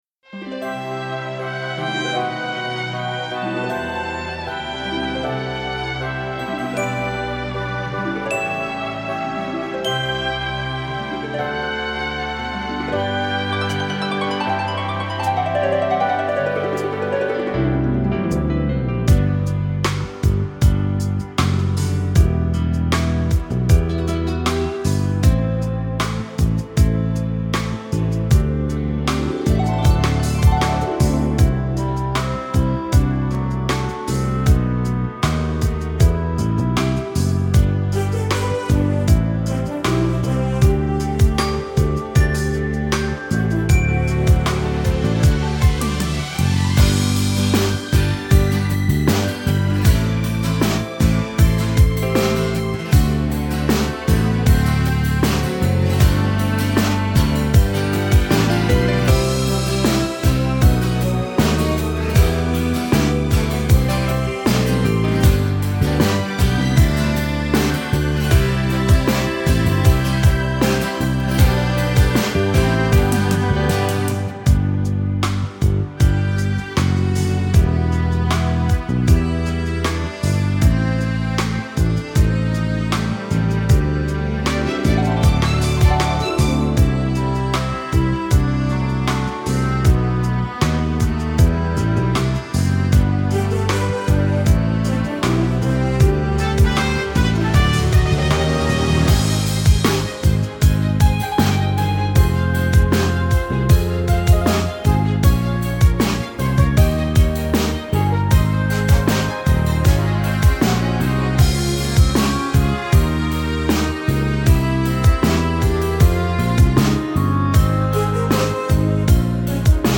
• Жанр: Детские песни
Слушать Минус